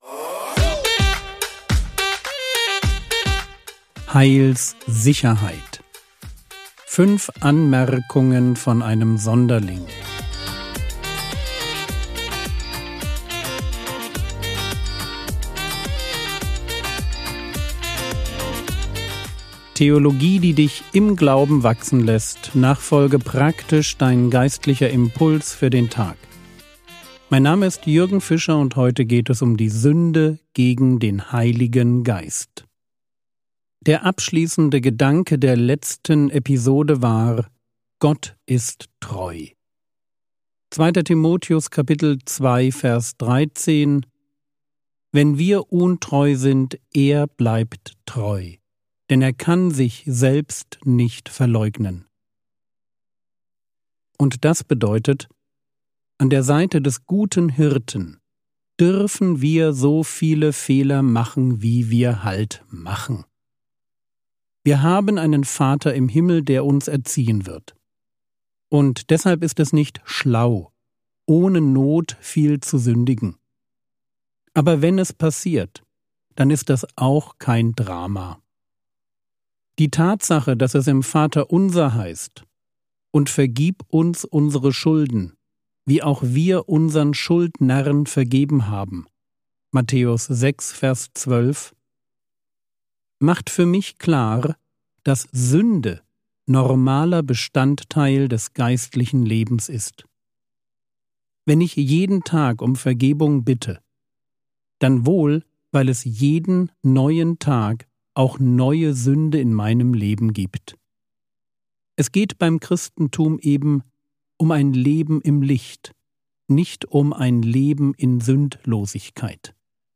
Heilssicherheit (4/5) ~ Frogwords Mini-Predigt Podcast